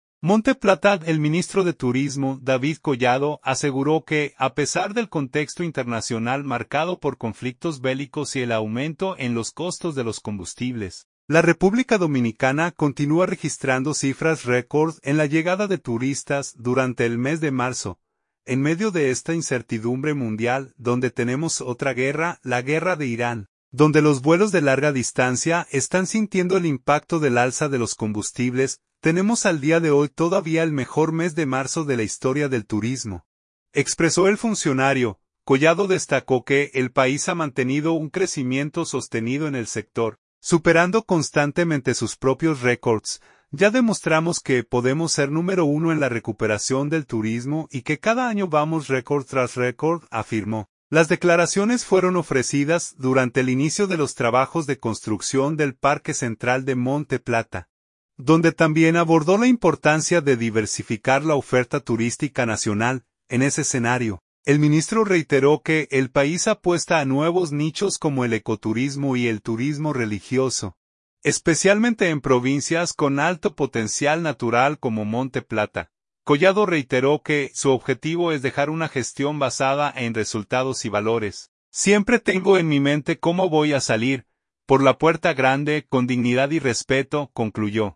Las declaraciones fueron ofrecidas durante el inicio de los trabajos de construcción del parque central de Monte Plata, donde también abordó la importancia de diversificar la oferta turística nacional.